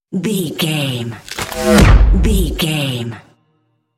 Dramatic whoosh to hit trailer
Sound Effects
Atonal
dark
intense
tension
woosh to hit